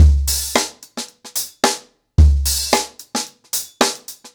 HarlemBrother-110BPM.19.wav